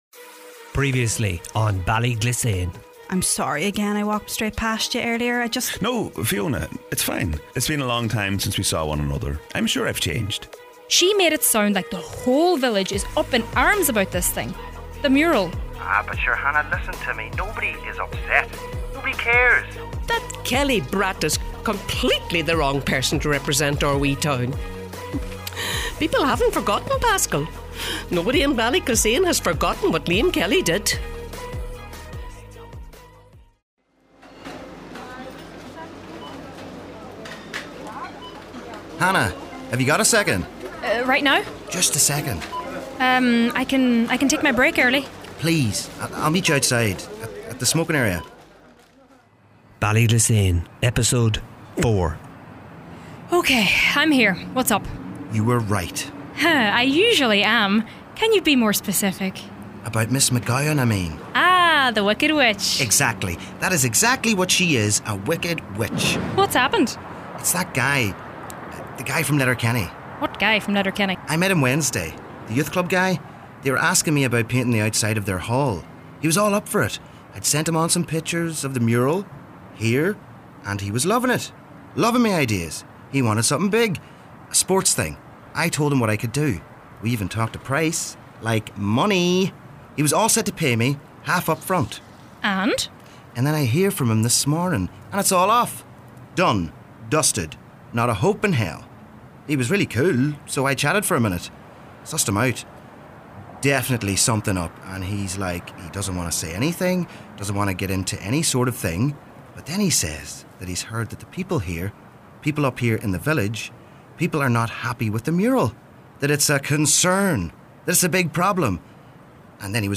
A drama series set in a small town somewhere in the heart of Donegal, where we meet various people who live in Ballyglissane and learn about their lives, their problems, and their secrets.